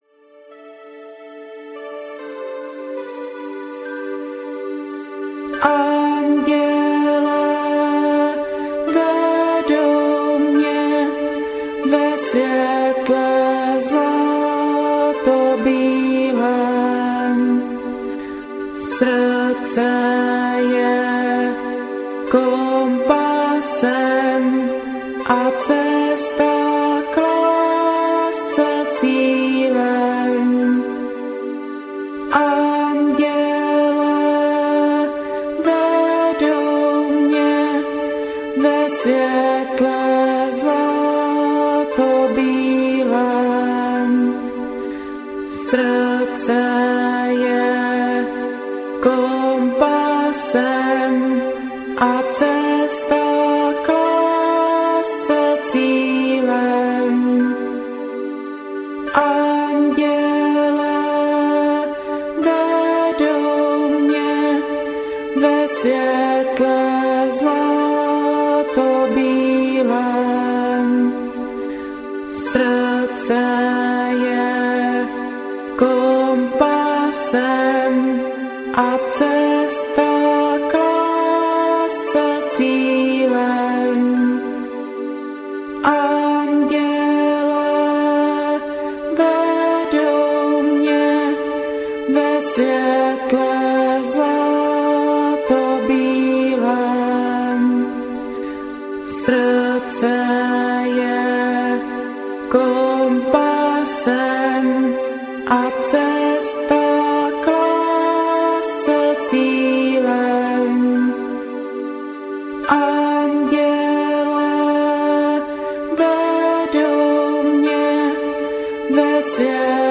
Text se opakuje osmnáctkrát.